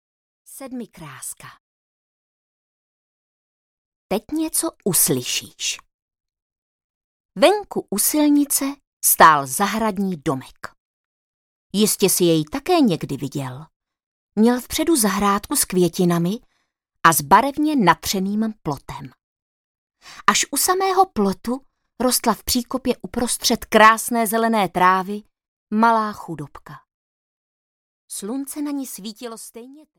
Sedmikráska audiokniha
Ukázka z knihy